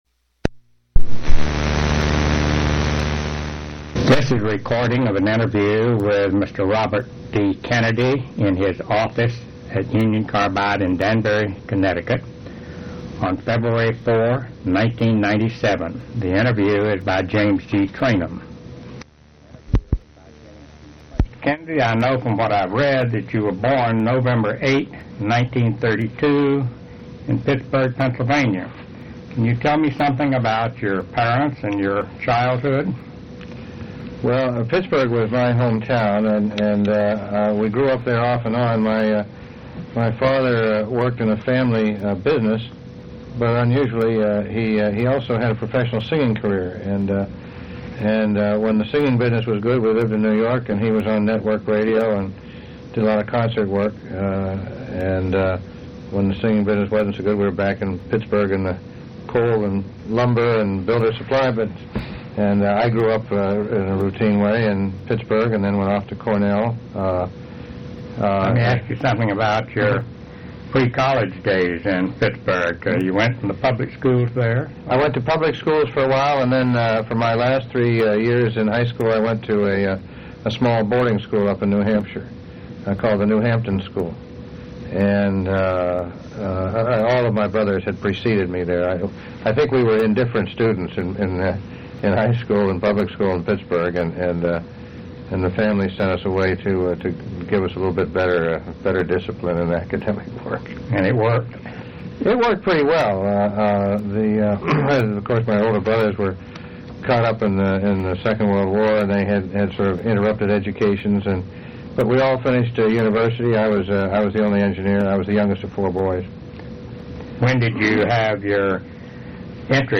Place of interview Connecticut--Danbury
Genre Oral histories